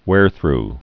(wârthr, hwâr-)